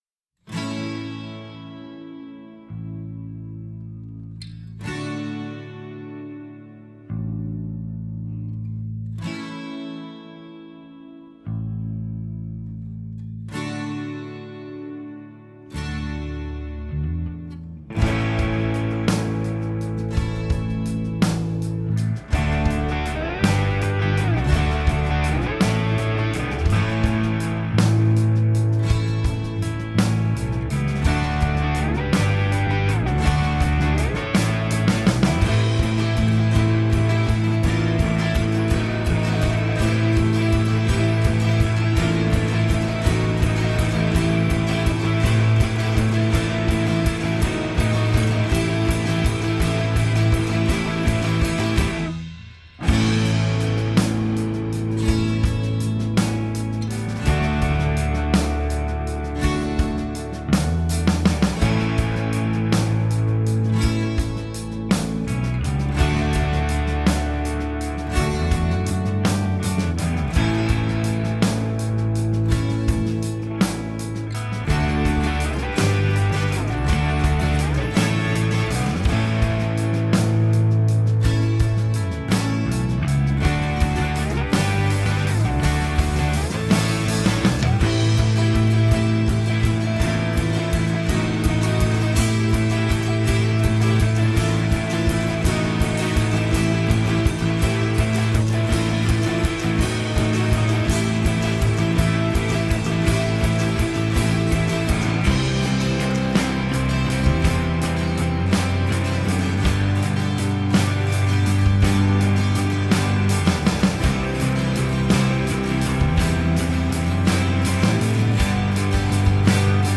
alternative rock outfit
Tagged as: Alt Rock, Rock, Instrumental